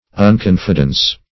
Unconfidence \Un*con"fi*dence\, n. Absence of confidence; uncertainty; doubt.